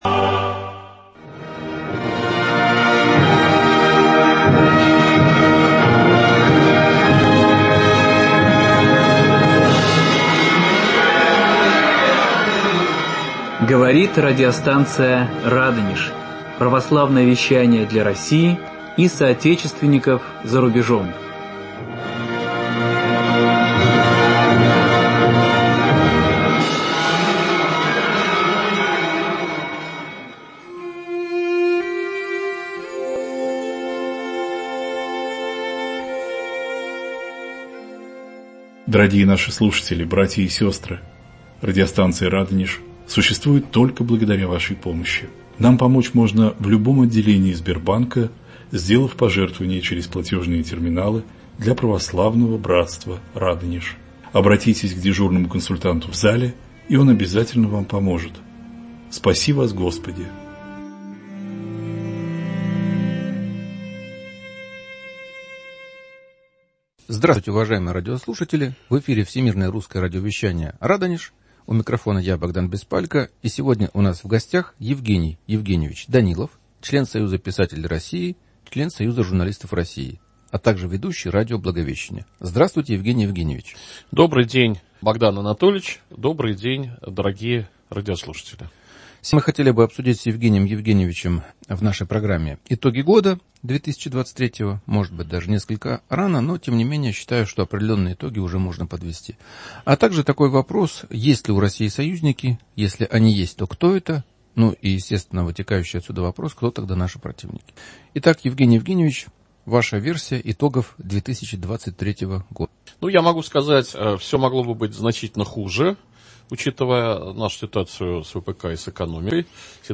В студии радио